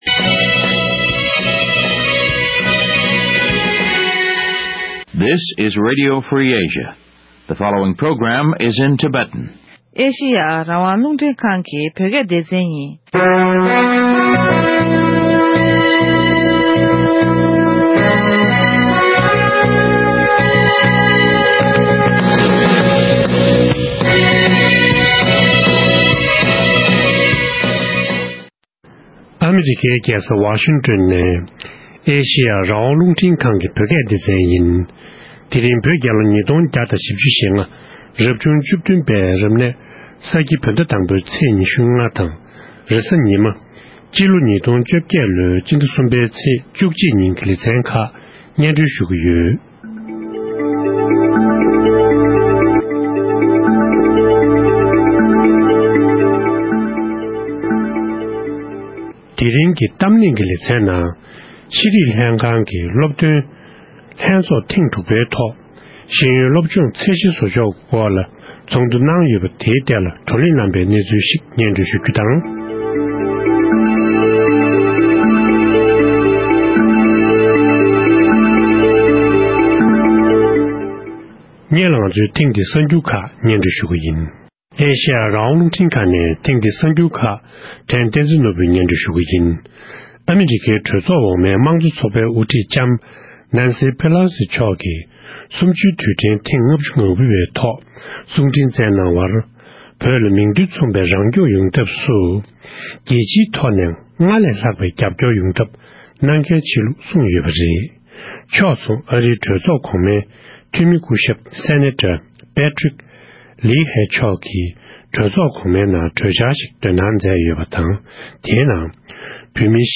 འབྲེལ་ཡོད་མི་སྣའི་ལྷན་གླེང་མོལ་ཞུས་པར་གསན་རོགས